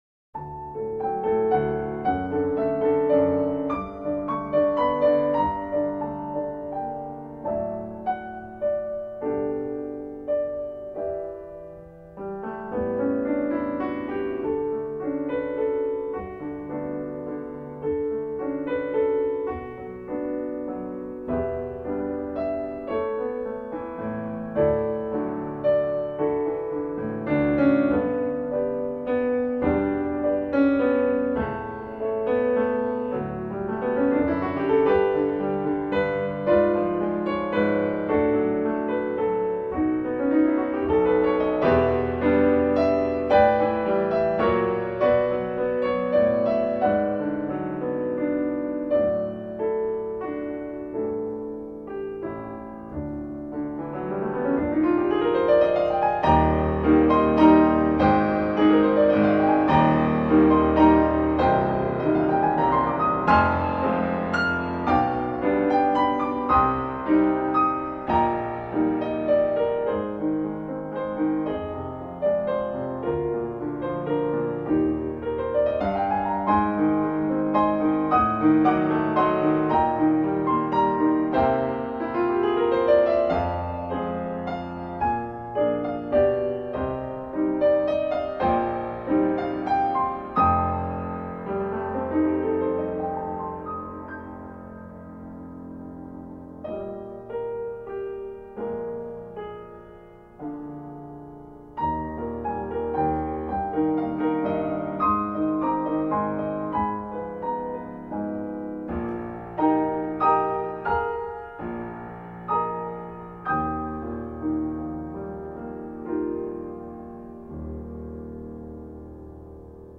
(performance)